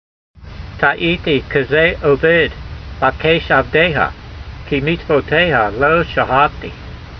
As you study the Old Testament in Hebrew, you can practice your speech in modern Hebrew style as you read.